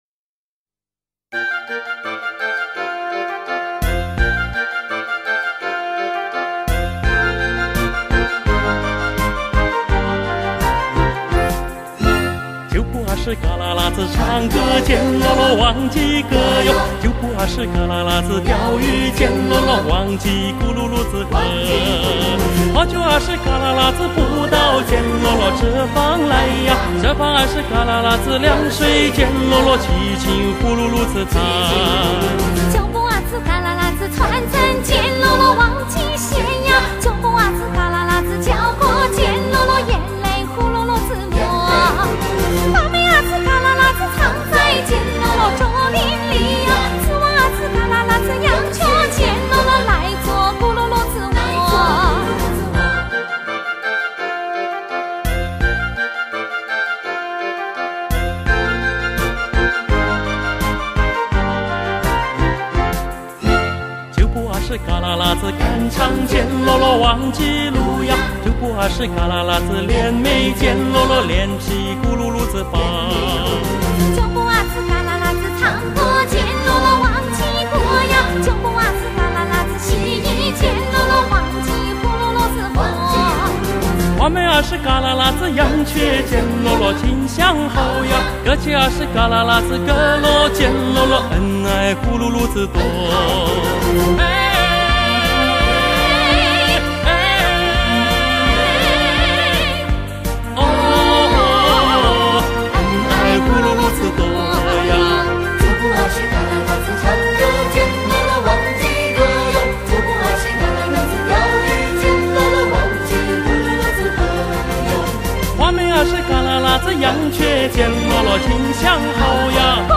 布依族民歌